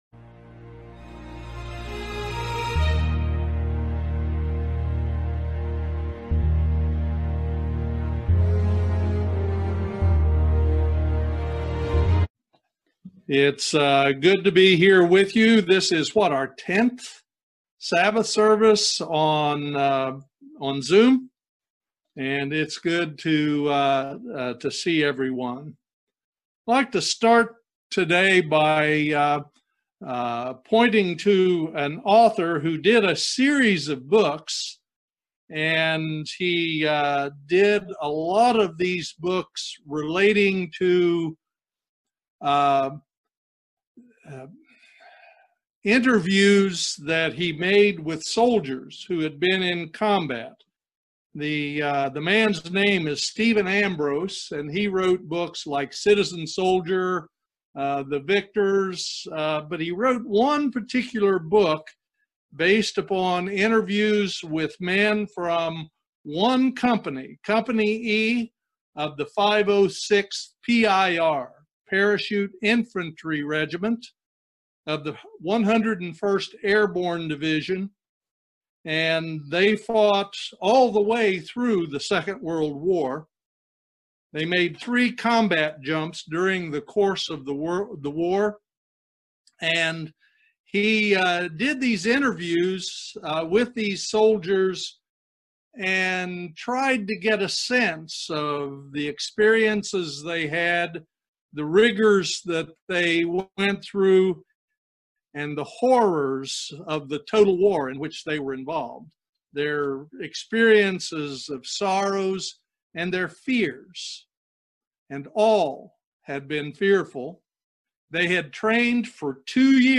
Join us for this excellent video sermon about conquering our fears. Fear is a part of who we are, but it doesn't have to limit us, and we can overcome it.
Given in Lexington, KY